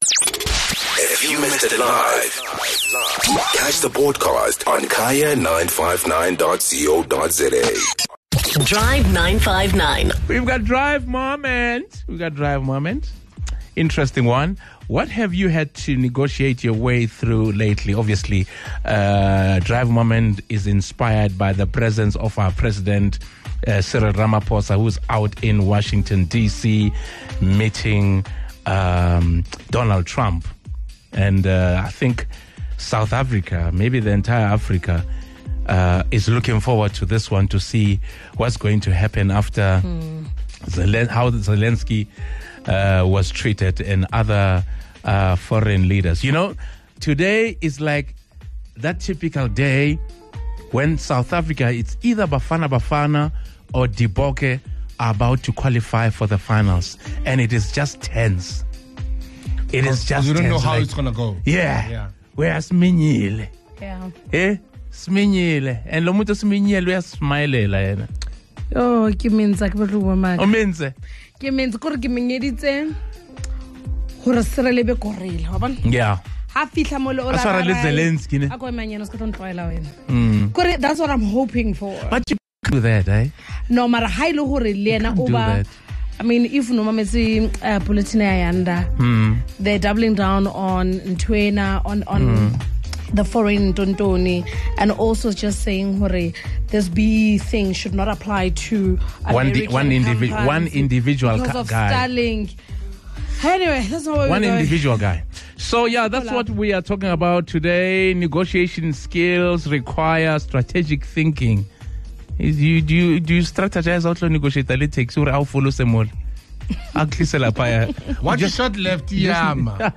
The Drive 959 team heard many different areas of life where our listeners are negotiating on a daily basis.